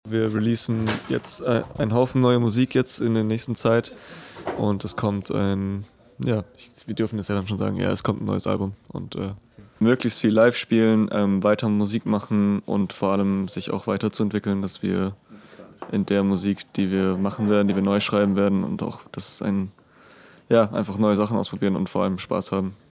O_Ton_3.mp3